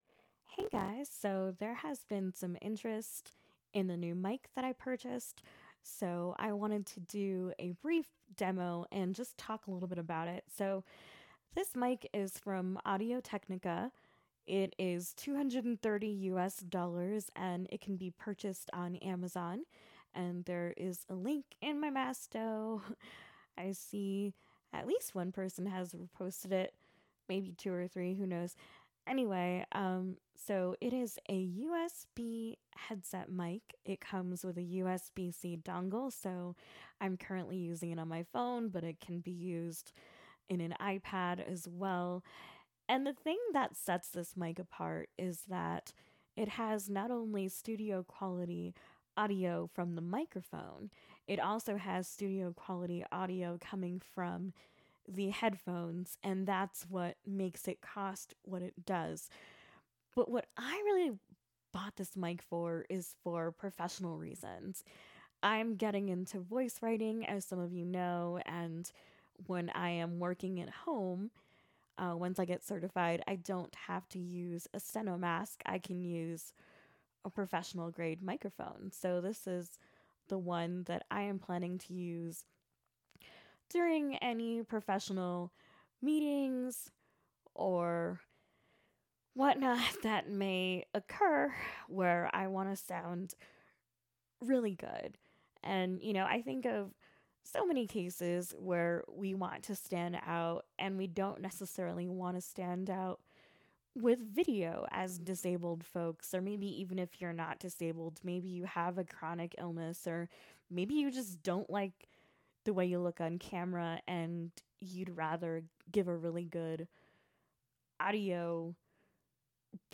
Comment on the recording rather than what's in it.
an impromptu demo of my new Audio Technica headset mic.